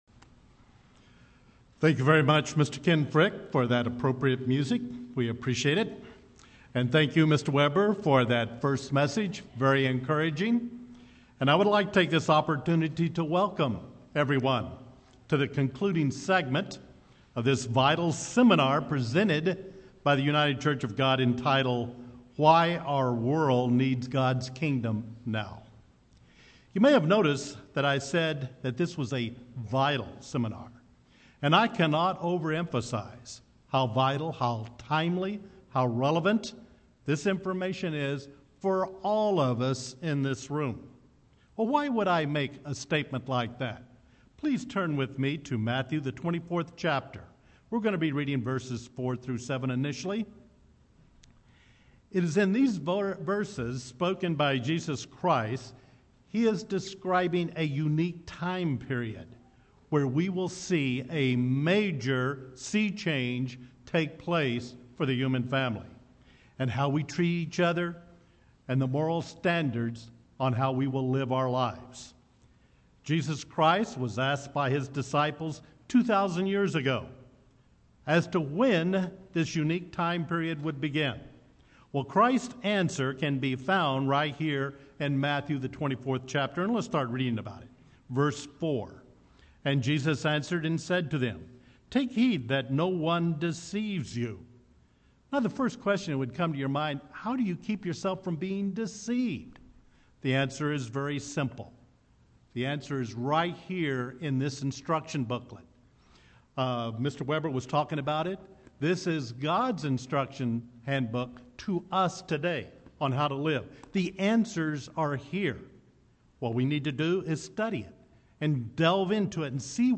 Matthew 24:29-31 describes the coming of the Son of Man coming with his angels. This will be the start of God’s intervention and establishment of the Kingdom of God here on earth. Learn more in this Kingdom of God seminar.